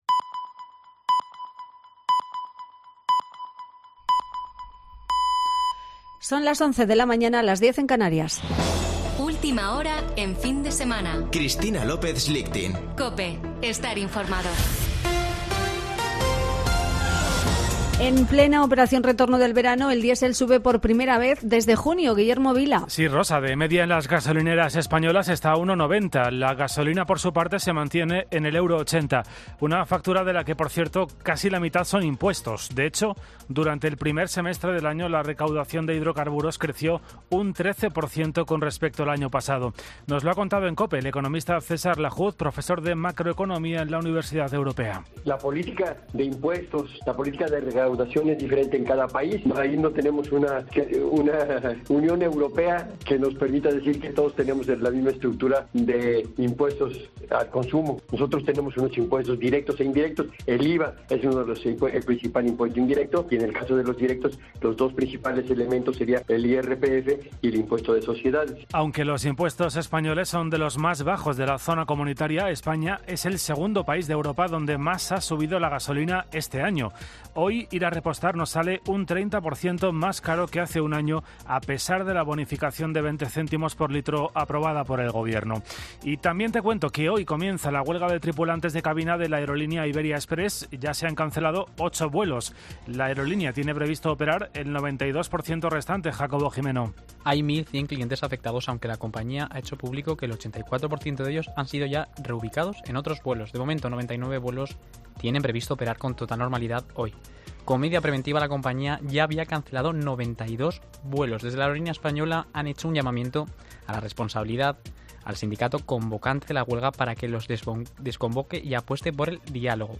Boletín de noticias de COPE del 28 de agosto de 2022 a las 11.00 horas